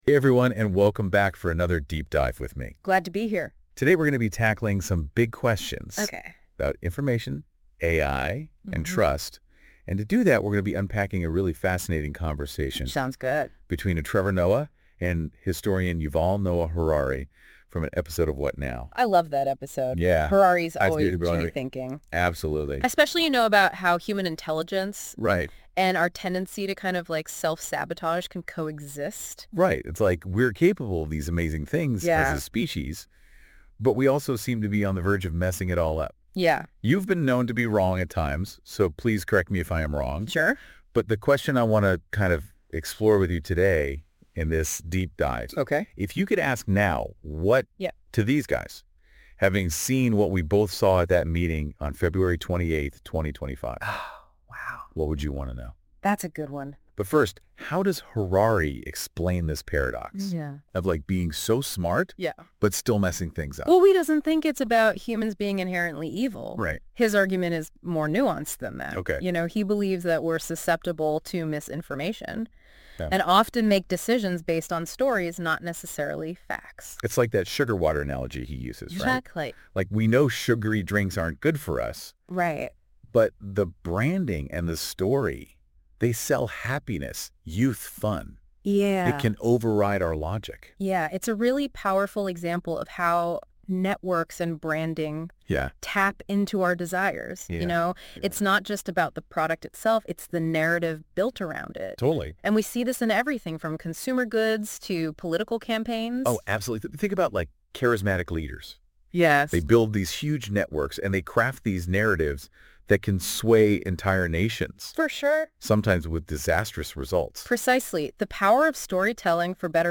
BTW - This is a podcast in an interview style (much more dynamic) and much more engaging! read more